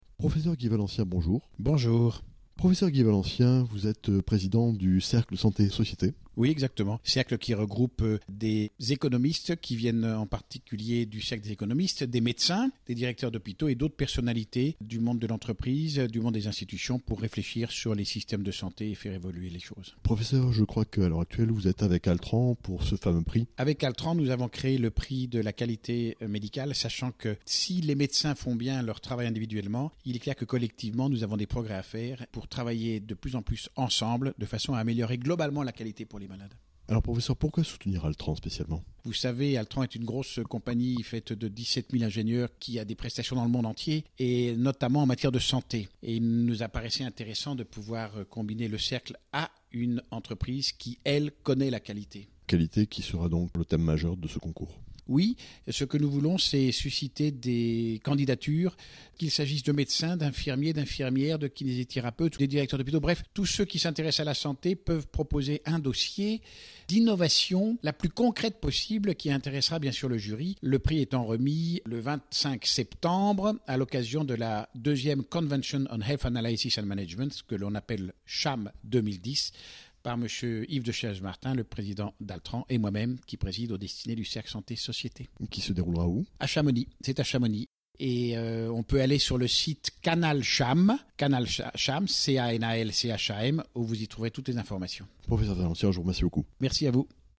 Prix Altran de la qualité médicale. 2010